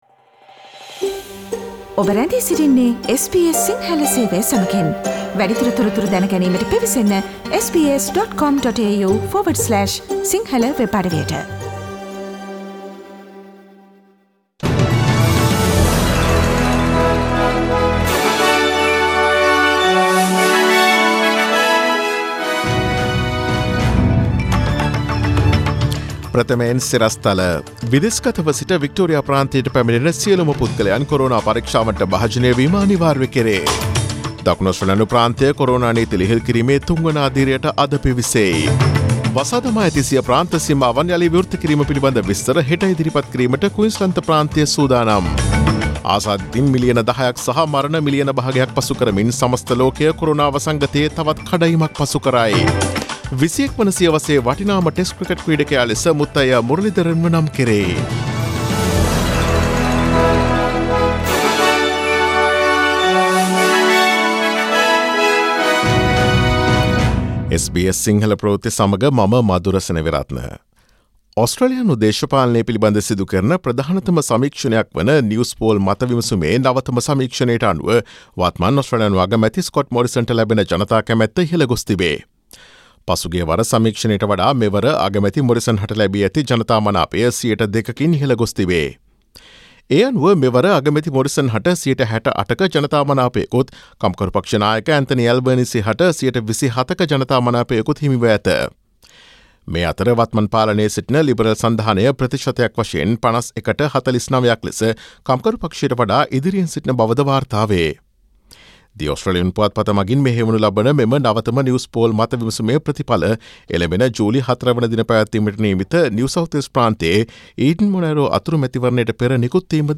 Daily News bulletin of SBS Sinhala Service: Monday 29 June 2020